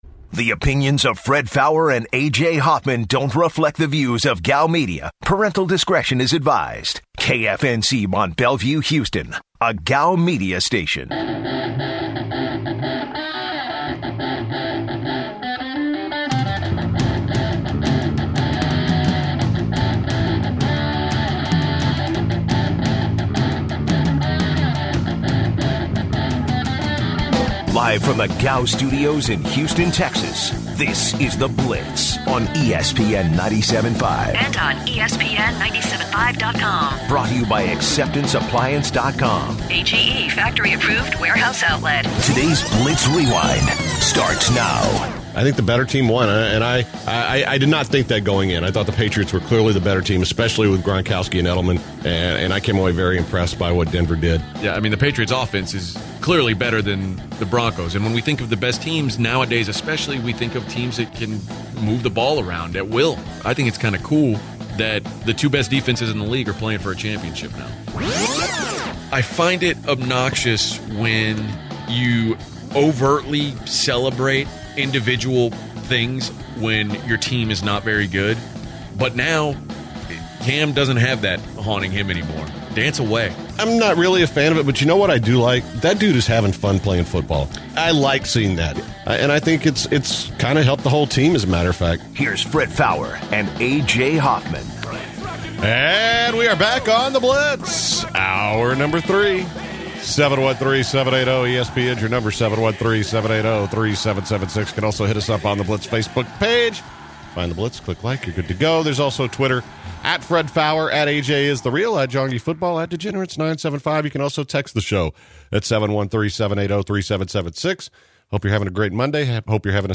and discuss with callers about college basketball around the nation.